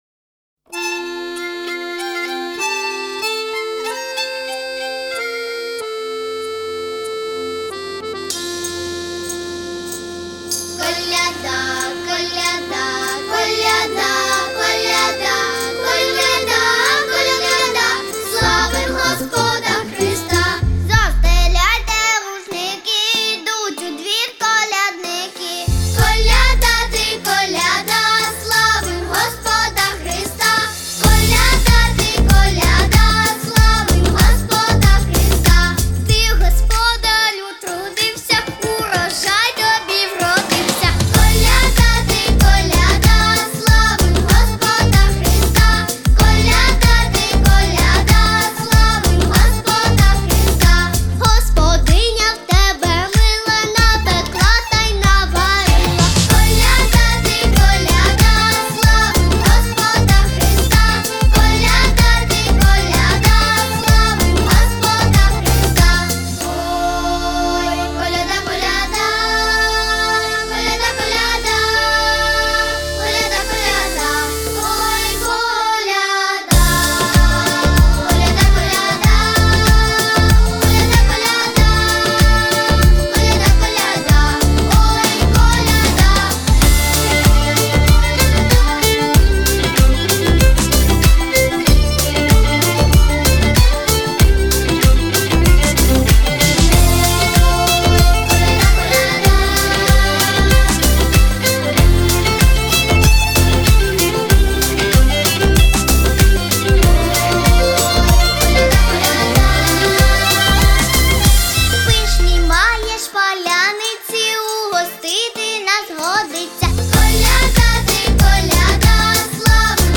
Ноты для детского ансамбля